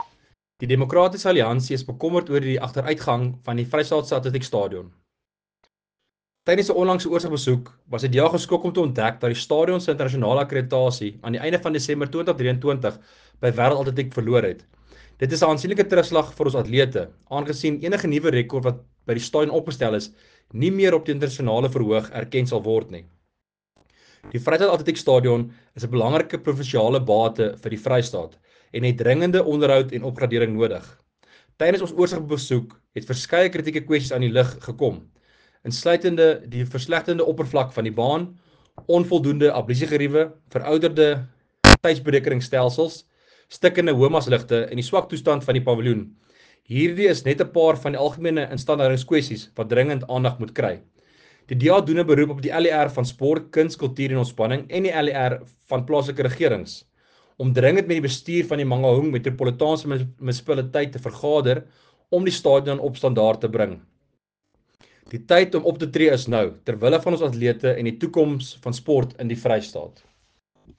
Afrikaans soundbites by Werner Pretorius MPL and images, here, here, here and here